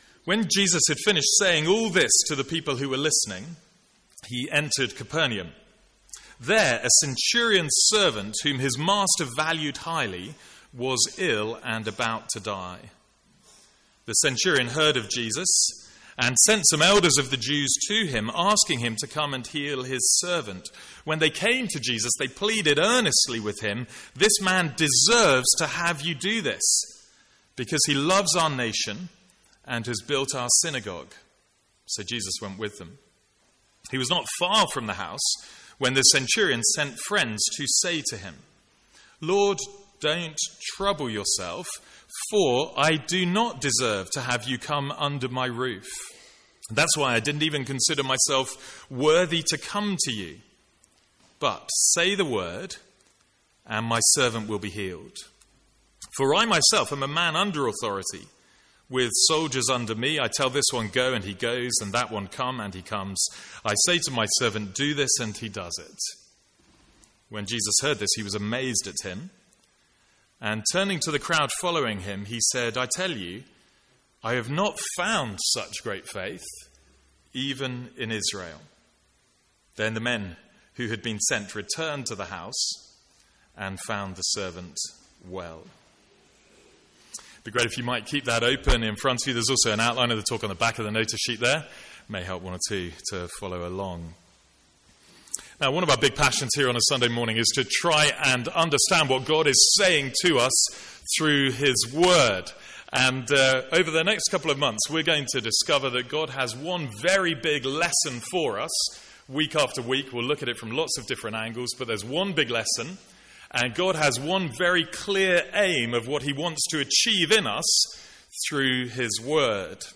Sermons | St Andrews Free Church
From the Sunday morning series in Luke.